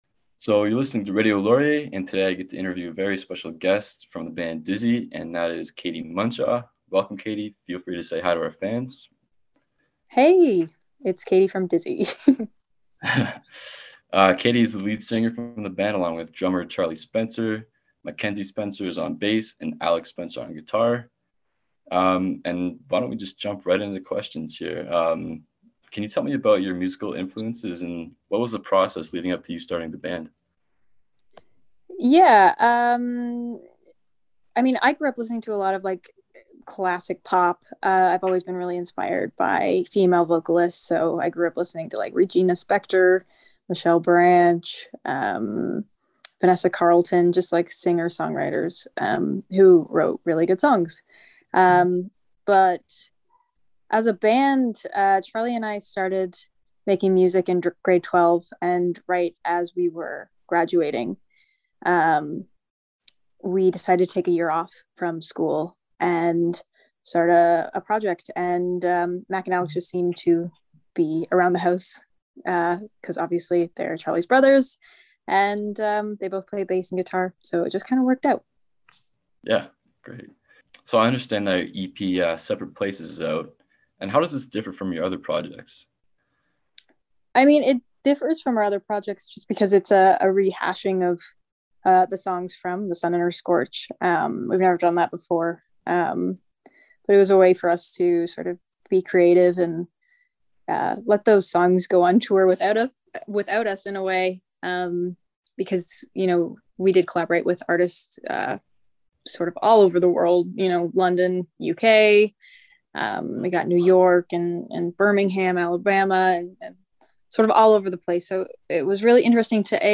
Band Interviews